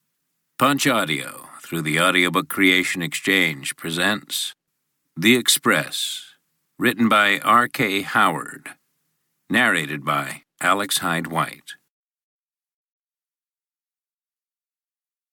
The Express (EN) audiokniha
Ukázka z knihy